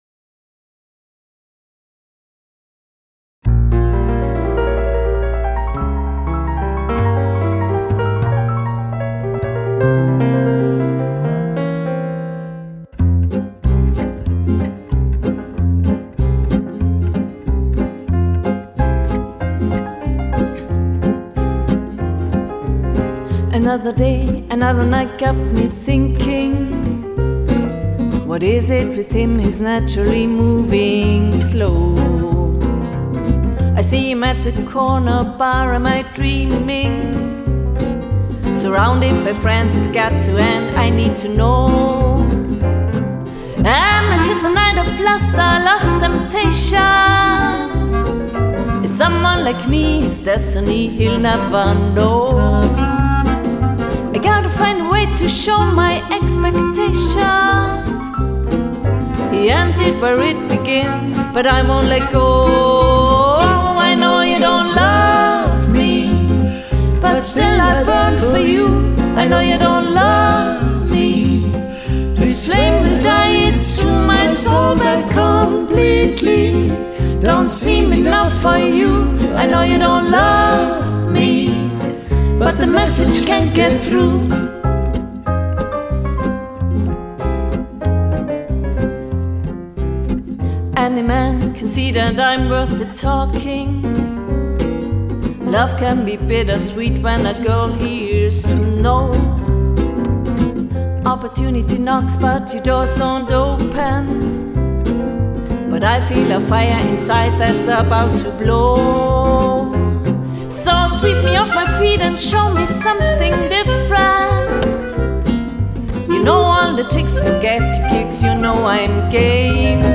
gitarre, gesang
akkordeon, klavier, kontrabass, gesang
klarinette, flöte, saxophon, gesang